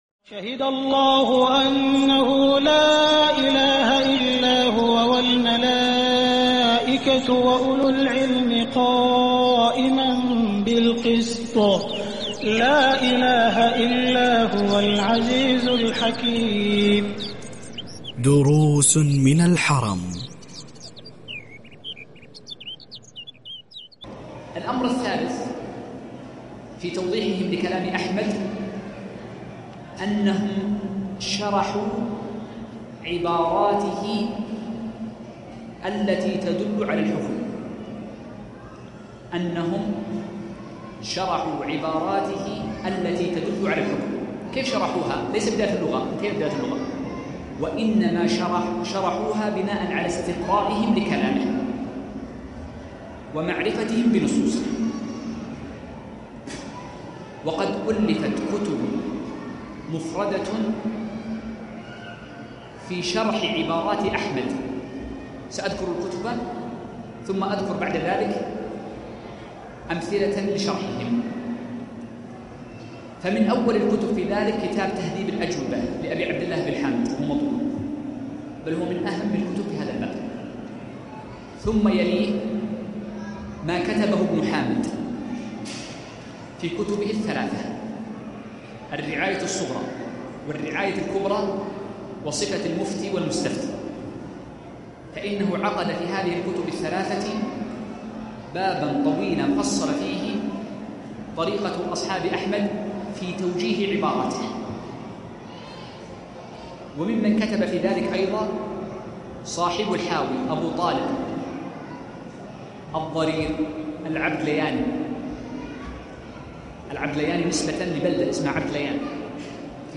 المدخل إلى مذهب الإمام احمد - الدرس الثاني ج2